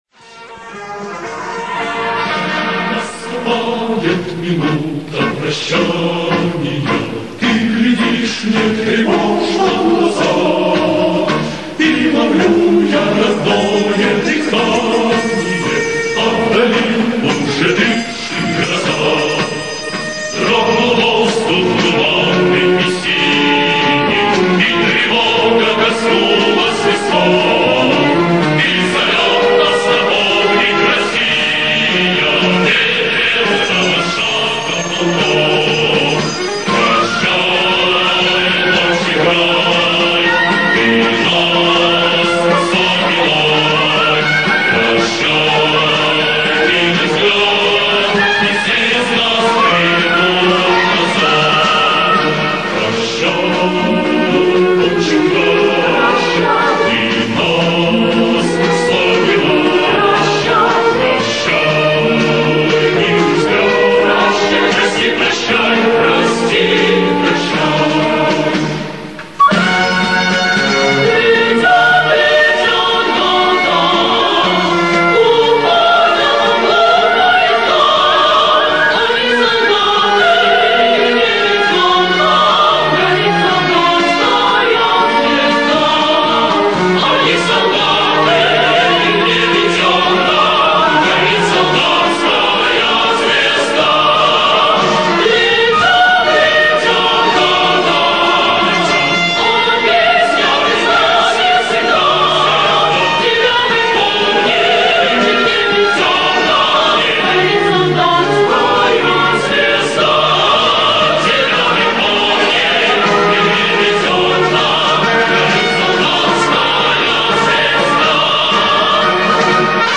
Марш «Прощание славянки» В.И. Агапкина на слова В.Я. Лазарева